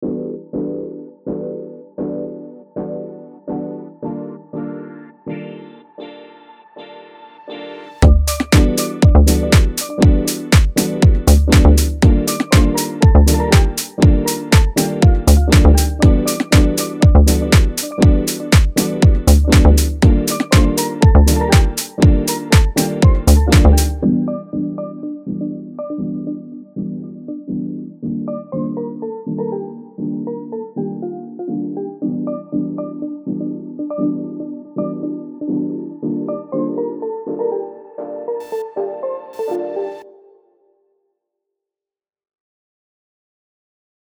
Influenced by 90's digital piano, the original source for this comes from a field recording of an interface.